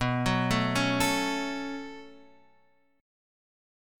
B7sus2 Chord